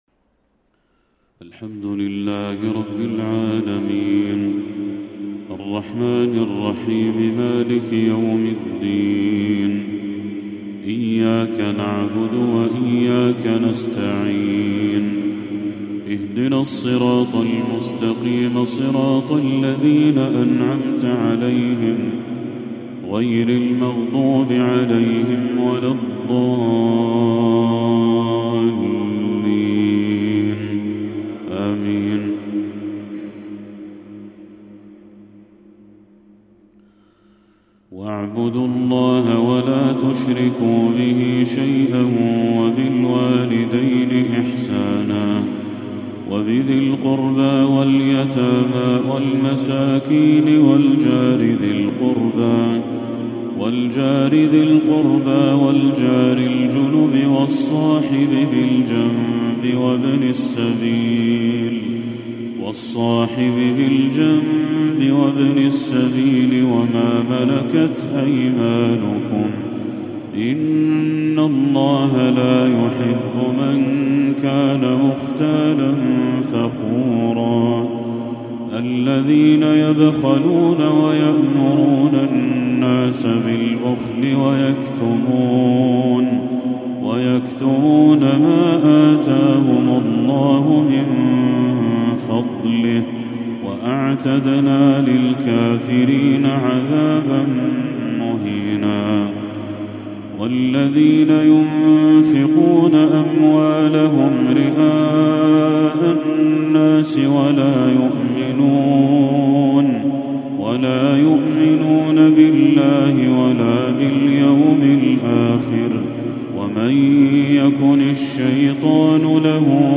تلاوة بديعة من سورة النساء للشيخ بدر التركي | عشاء 17 ربيع الأول 1446هـ > 1446هـ > تلاوات الشيخ بدر التركي > المزيد - تلاوات الحرمين